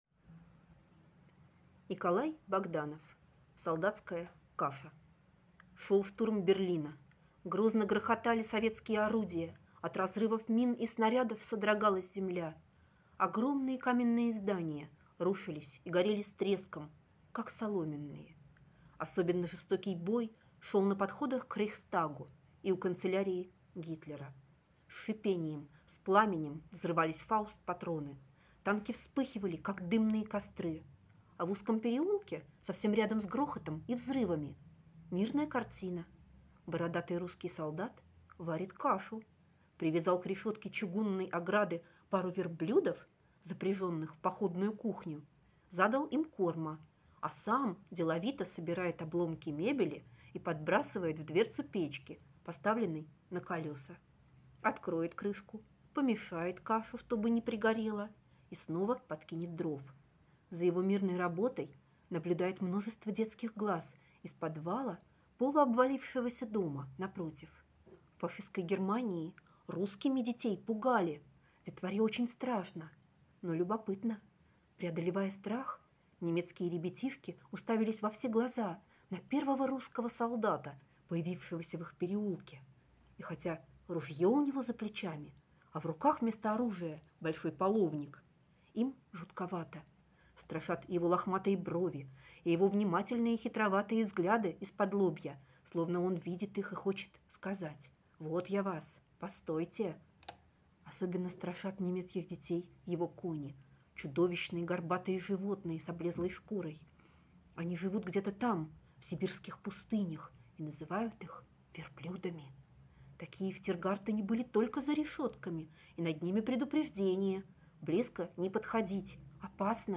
Аудиорассказ «Солдатская каша»